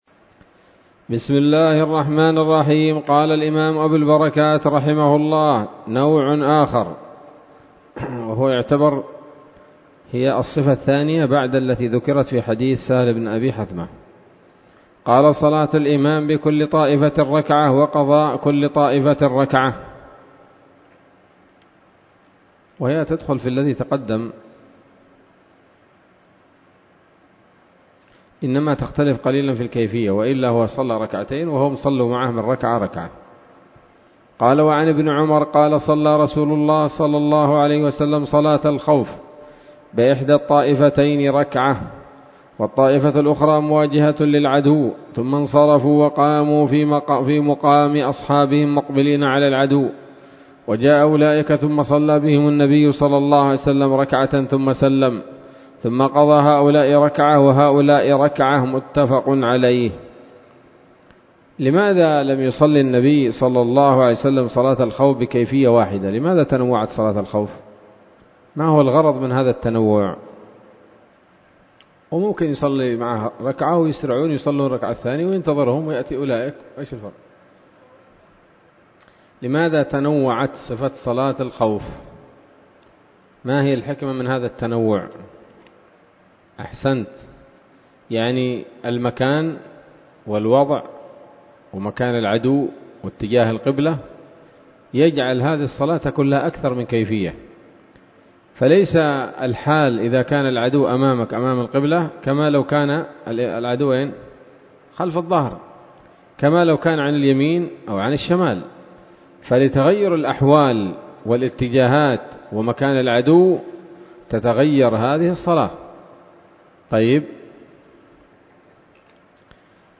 الدرس الثاني من ‌‌‌‌كتاب صلاة الخوف من نيل الأوطار